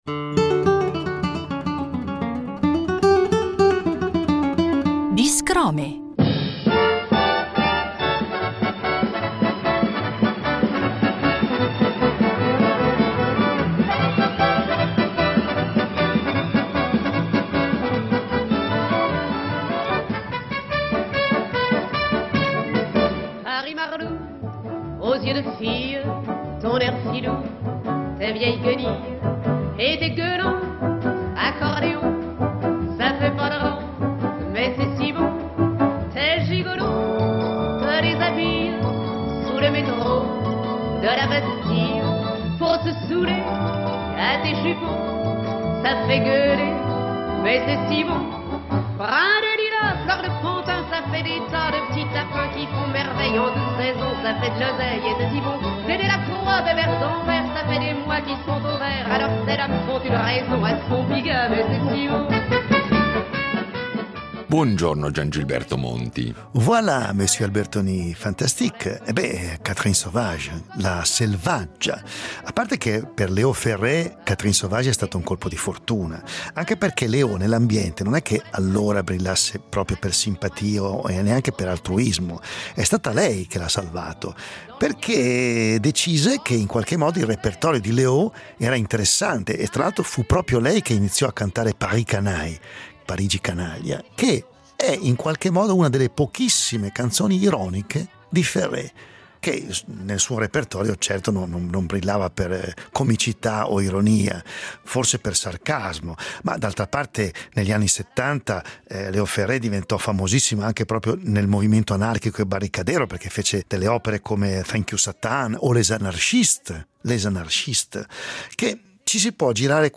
registrato dal vivo con una band di stampo jazzistico